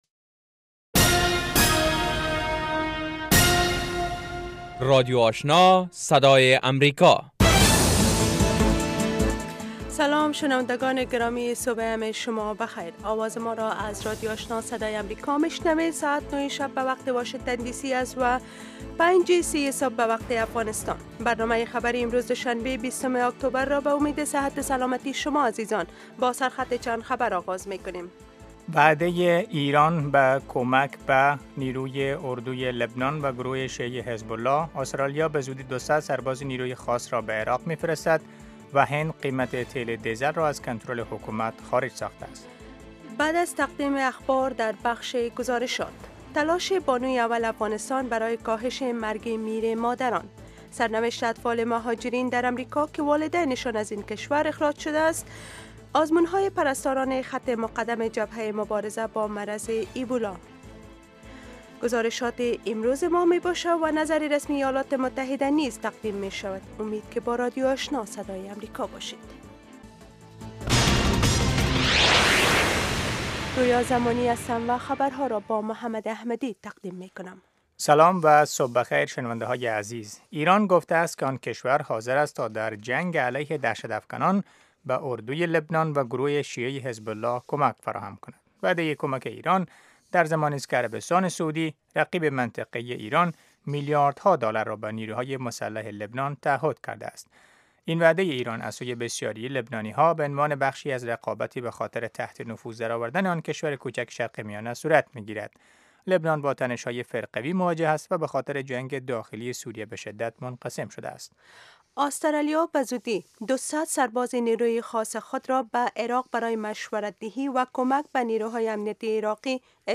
برنامه خبری صبح